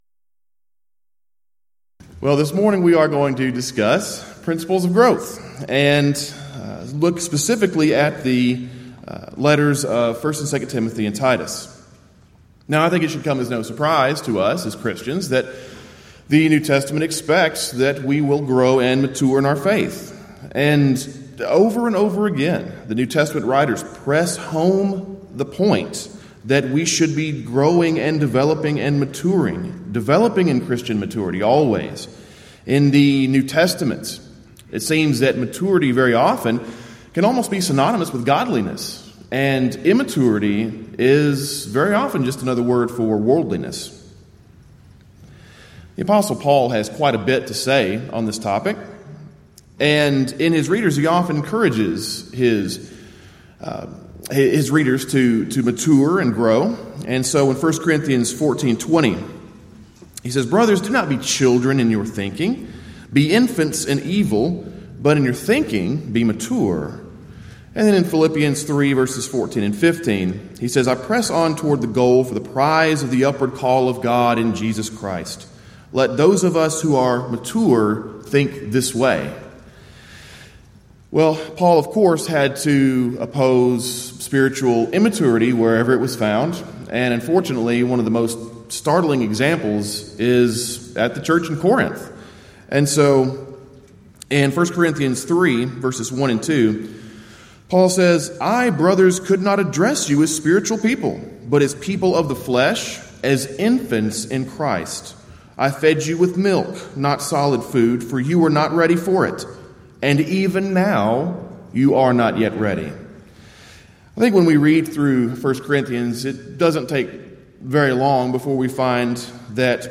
Event: 33rd Annual Southwest Lectures Theme/Title: Equipping To Serve: Lessons from the Pastoral Epistles
lecture